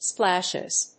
/ˈsplæʃɪz(米国英語)/